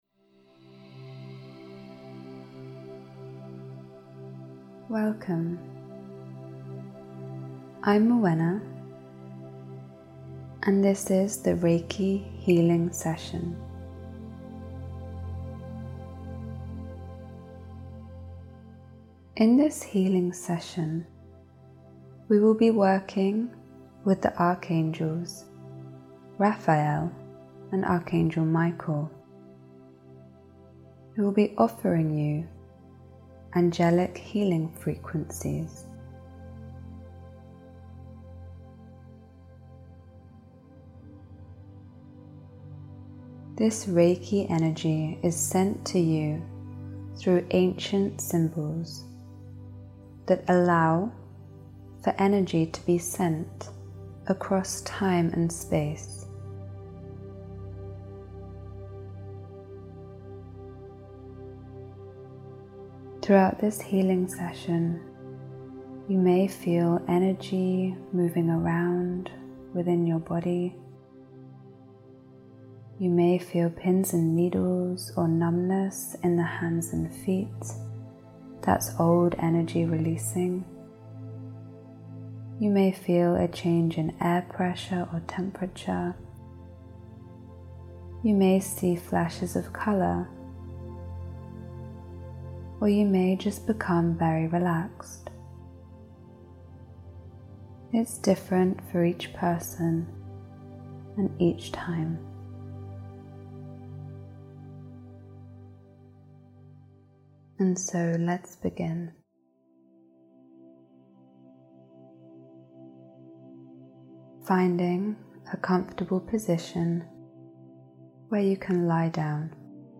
This Reiki Healing Session will switch on the flow of Healing Energy for you to receive as you listen to the guided session.